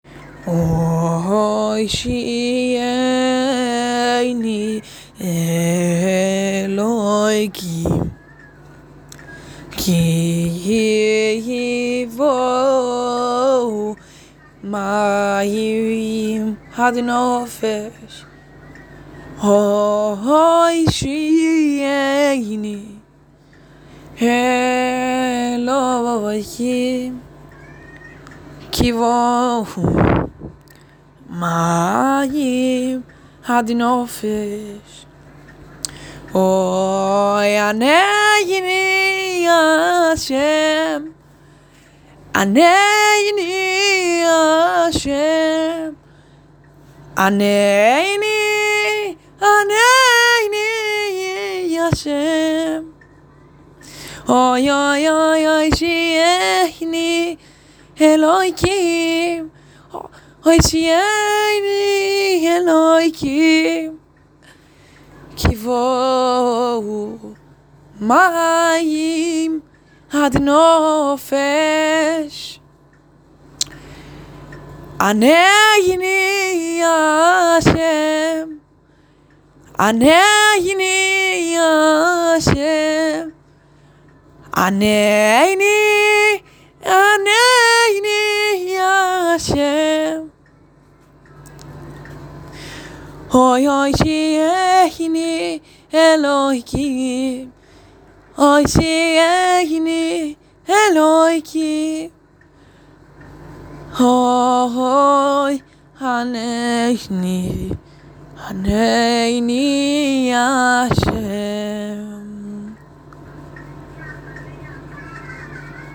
ענני ה'_ללא ליווי.m4a